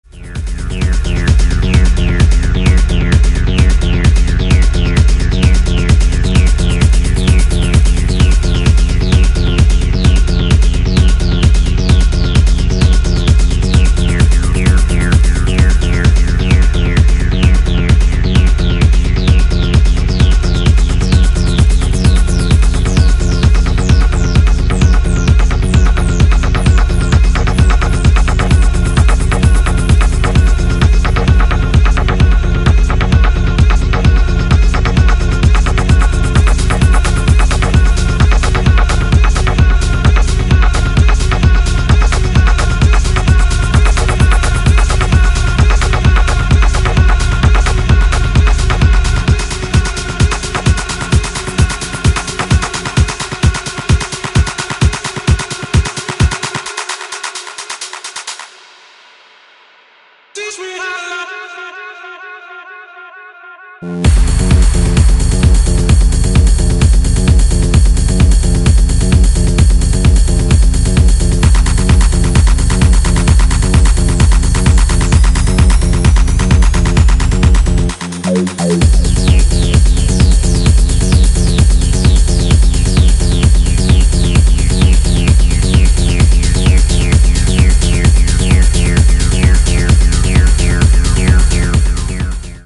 his deep, Detroit-influenced cut from 2014.